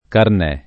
carnè [ karn $+ ] s. m.